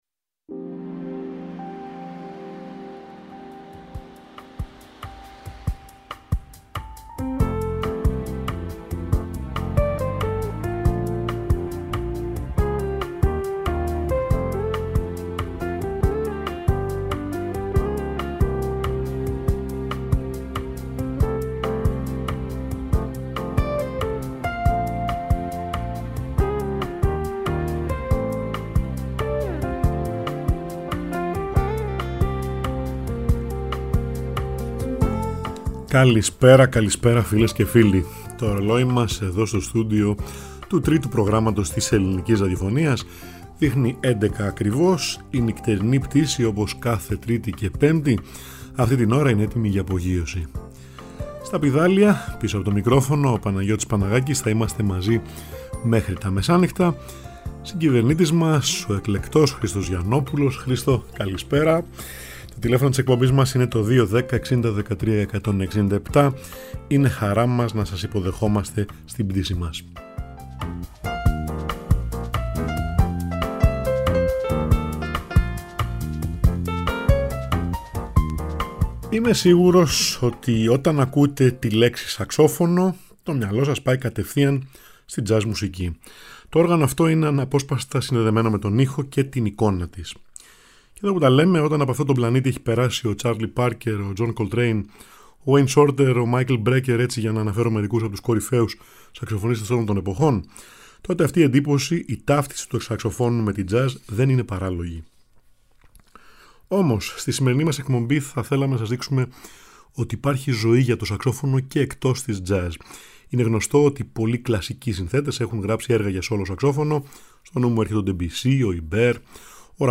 Το σαξόφωνο έξω από το περιβάλλον της jazz